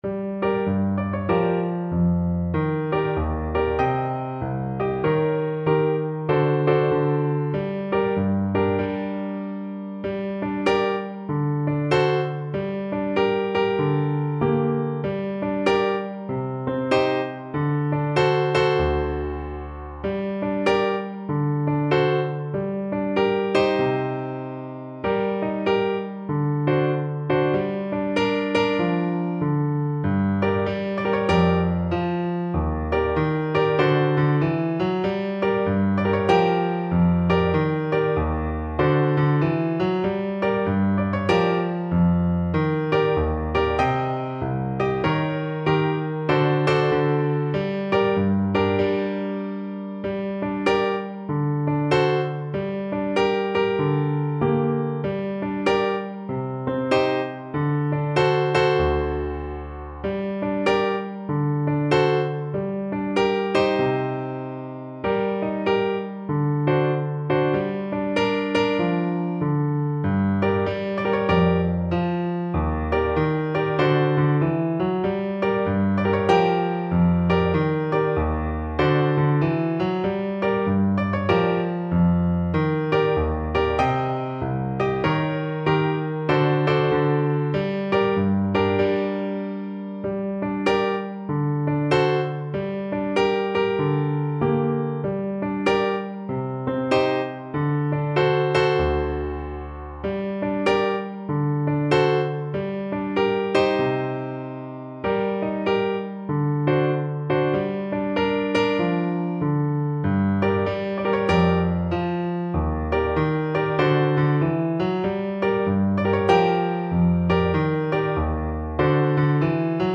ClarinetPiano
4/4 (View more 4/4 Music)
Medium Swing = 96